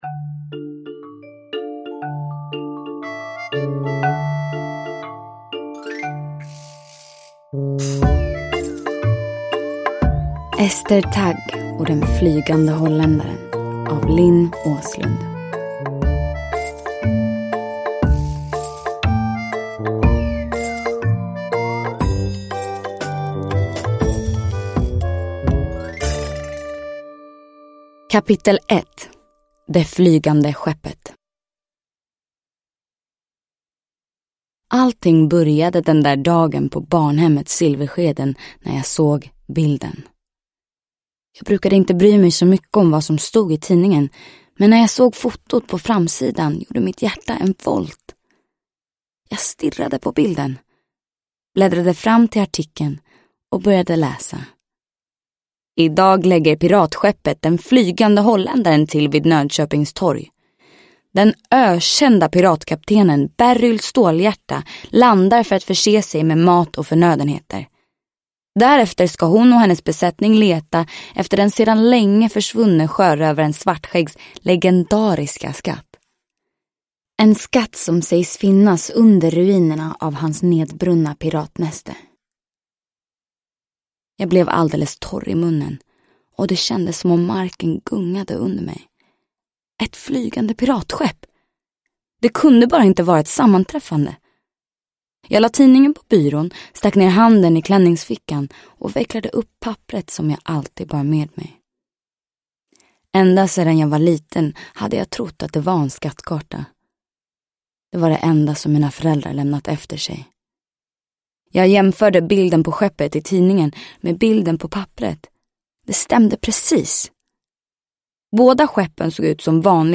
Ester Tagg och Den flygande holländaren – Ljudbok – Laddas ner
Uppläsare: Hedda Stiernstedt